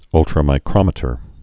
(ŭltrə-mī-krŏmĭ-tər)